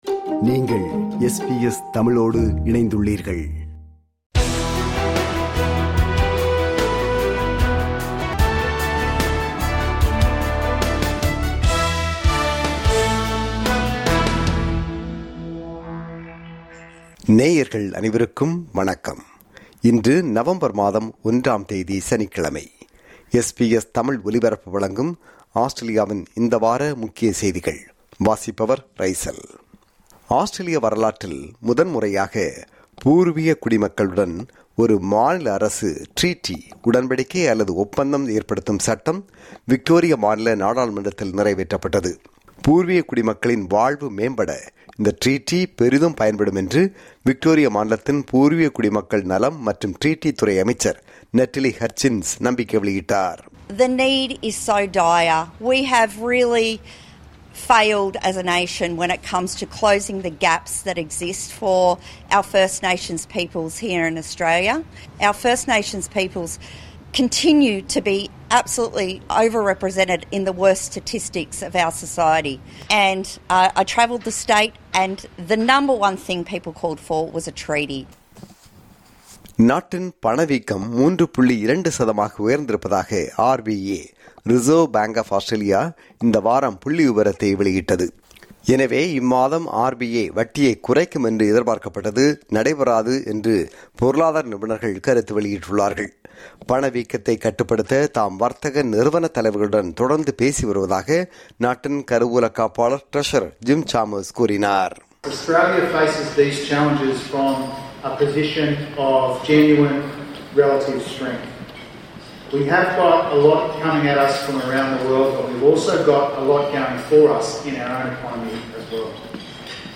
ஆஸ்திரேலியாவில் இந்த வாரம் (26 அக்டோபர் – 1 நவம்பர் 2025) நடந்த முக்கிய செய்திகளின் தொகுப்பு.